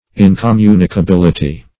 Search Result for " incommunicability" : The Collaborative International Dictionary of English v.0.48: Incommunicability \In`com*mu`ni*ca*bil"i*ty\, n. [Cf. F. incommunicabilit['e].] The quality or state of being incommunicable, or incapable of being imparted.